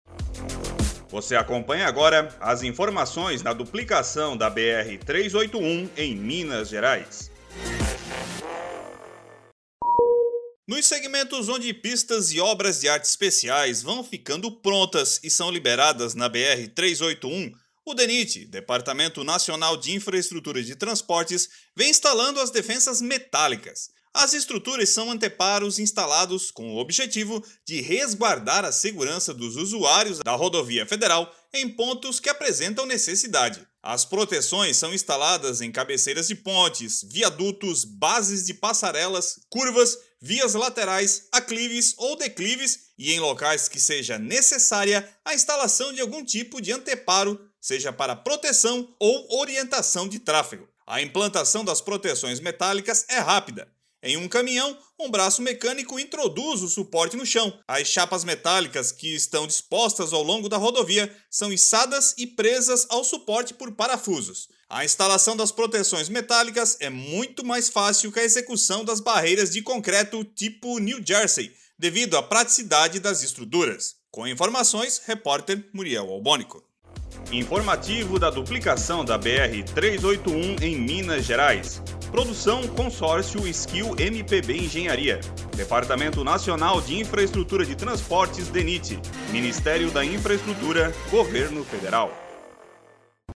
Ouça agora mesmo o informativo desta quinta-feira (30), sobre as obras de duplicação da Rodovia BR-381 no estado de Minas Gerais.